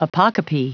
Prononciation du mot apocope en anglais (fichier audio)
Prononciation du mot : apocope